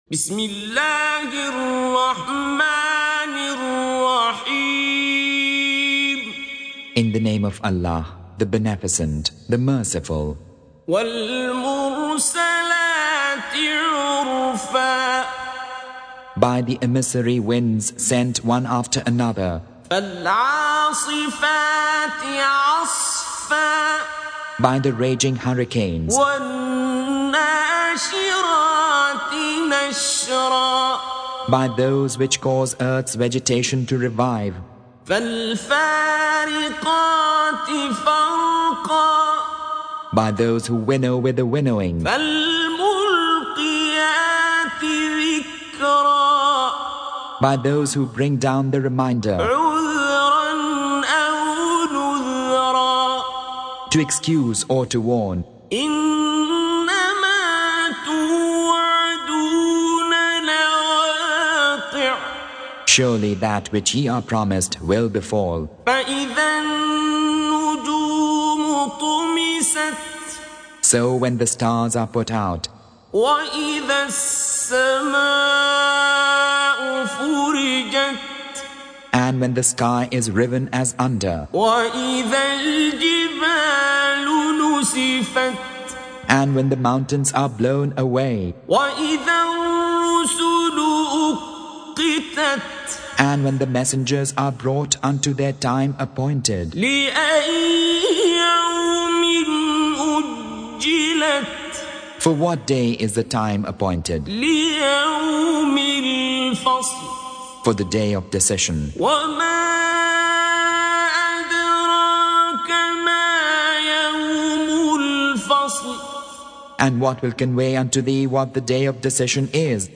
Surah Repeating تكرار السورة Download Surah حمّل السورة Reciting Mutarjamah Translation Audio for 77.